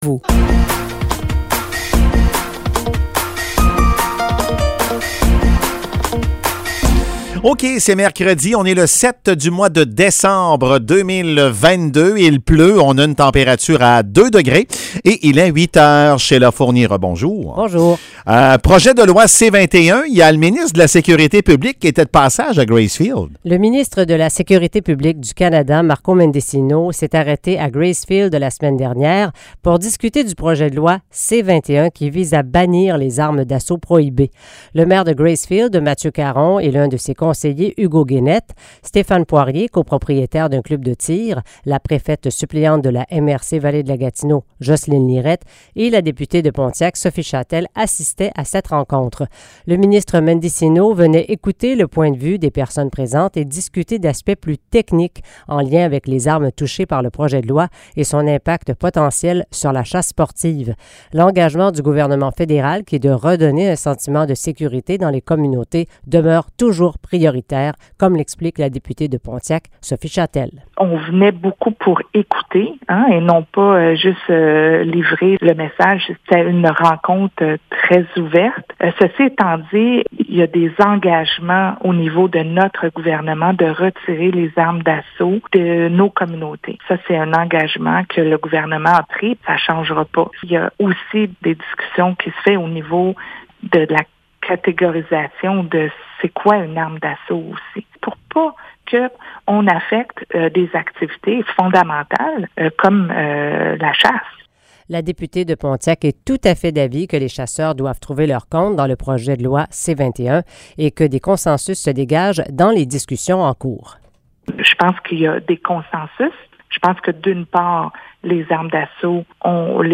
Nouvelles locales - 7 décembre 2022 - 8 h
CHGA FM vous informe tout au long de la journée. Retrouvez les nouvelles locales du mercredi 7 décembre 2022 à 8 h.